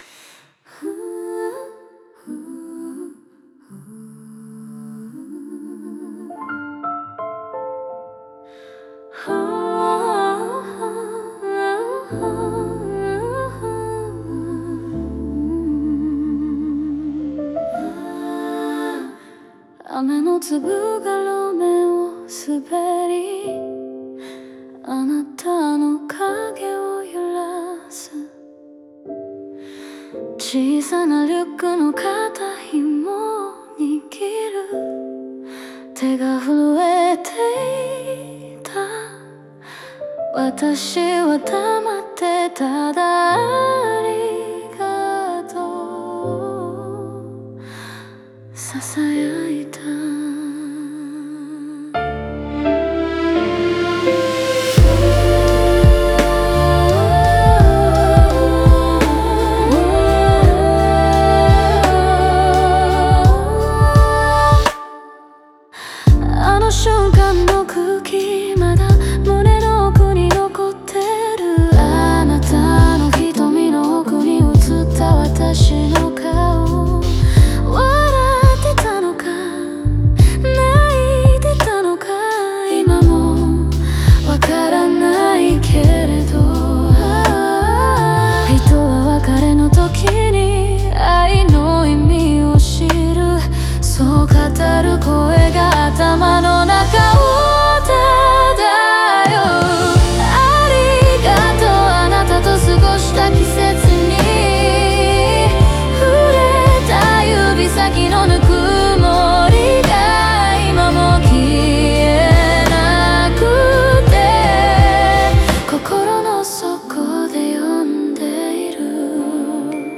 女性の柔らかい語り口で過去の記憶をリアルに描き出す叙情的なドキュメンタリーバラード
ピアノとストリングスが静寂の中に温もりを添え、雨音や呼吸が時間の流れを刻む。